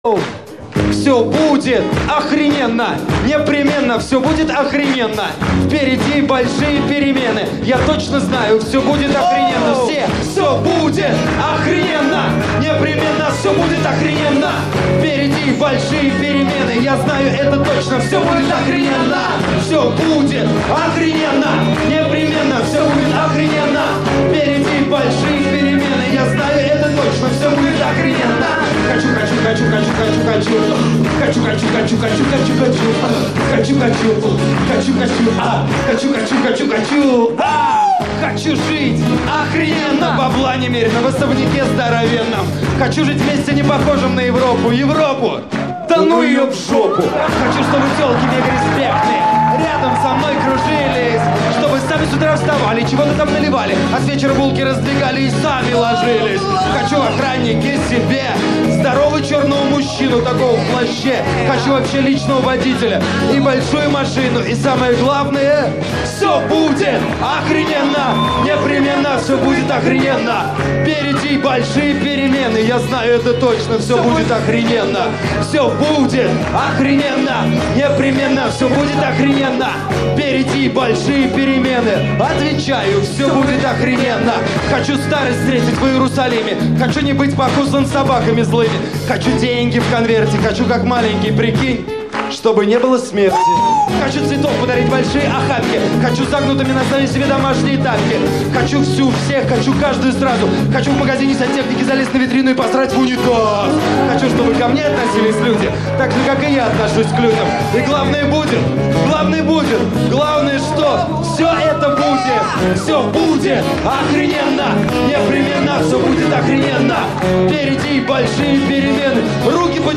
Правда версия пипец галимая) какая то не обработанная